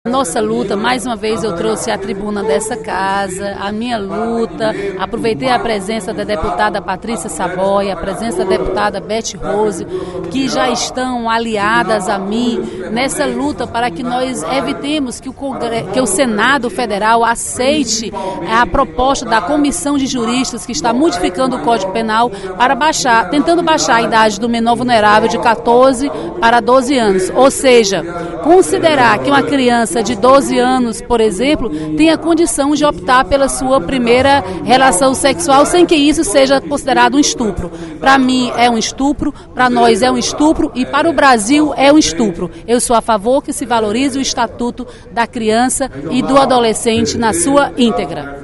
A deputada Dra.Silvana (PMDB) criticou, na sessão plenária desta sexta-feira (23/03), a revisão do Código Penal em curso no Congresso Nacional, que pretende reduzir a idade sexual de 14 para 12 anos.